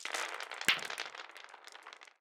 弹珠2.wav